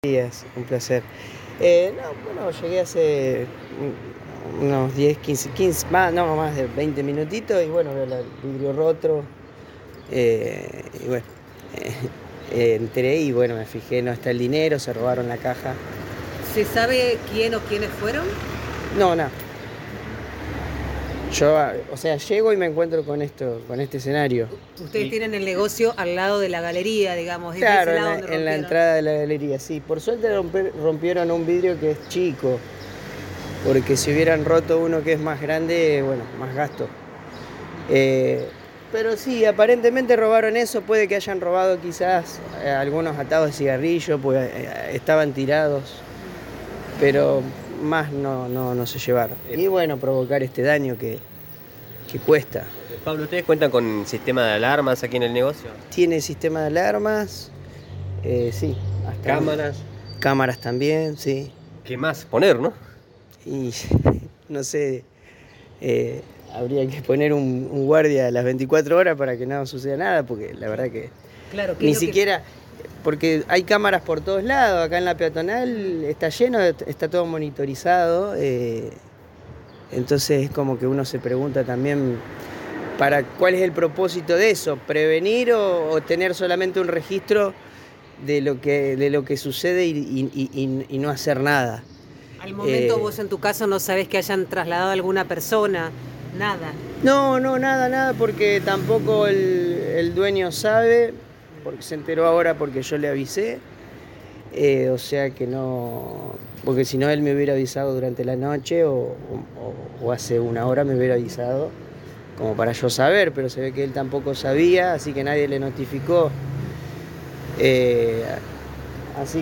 Declaraciones del encargado del kiosco vandalizado